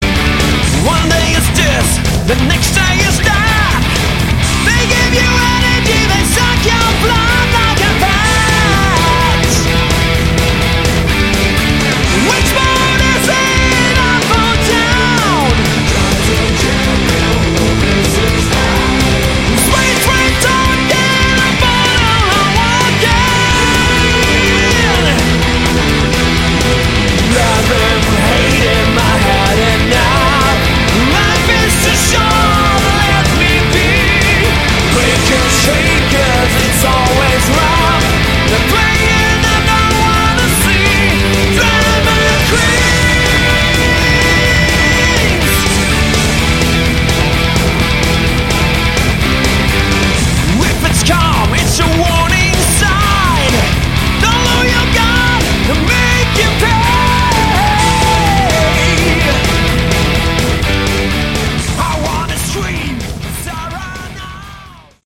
Category: Hard Rock
Guitars
Keyboards
Bass
Drums
Vocals